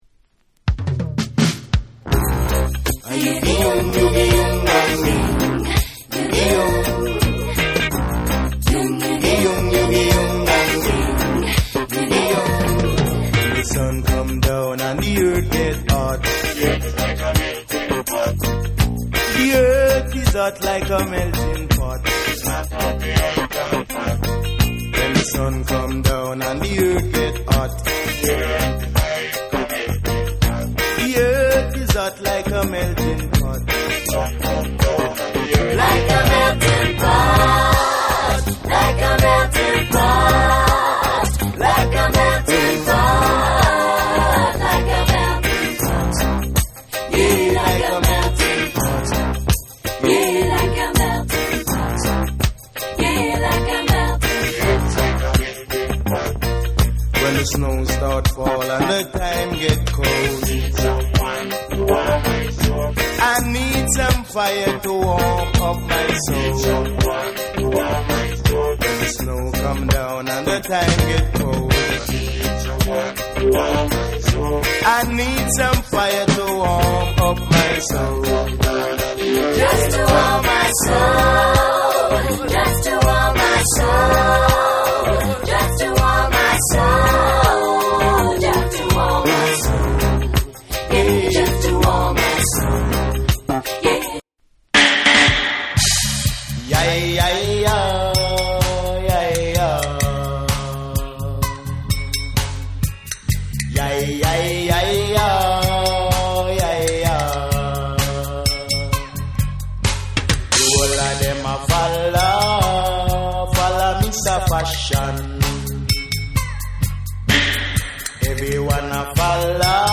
ヴォコーダー・ヴォイスを使った個性的なトースティングが冴え渡る1。
REGGAE & DUB